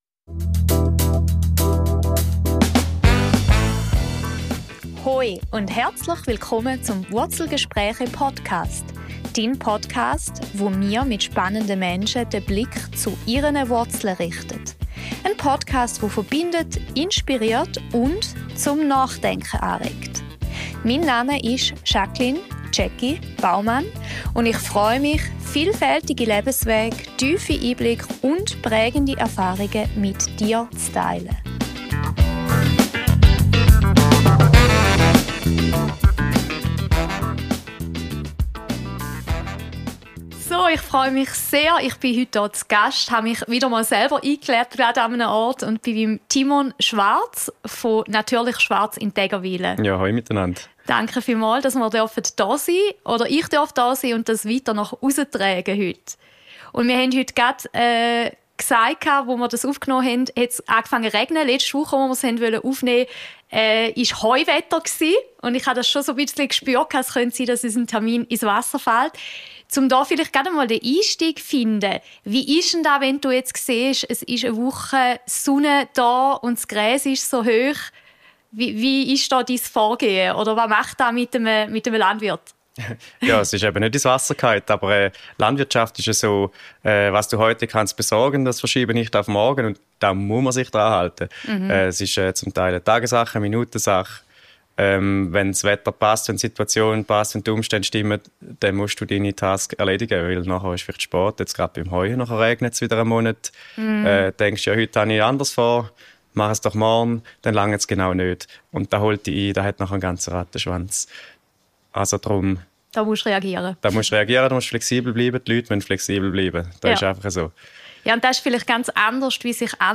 Und wie sieht modernes Unternehmertum in der Landwirtschaft aus? Ein Gespräch über Bodenständigkeit, Visionen und die Kraft, Dinge anders zu machen.